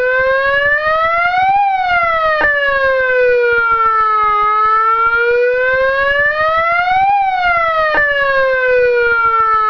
Sirena electrónica de gran potencia direccional
Aumento progresivo del volumen - PSO (Progressive Sound Output).
MODULADO
MODULADO.wav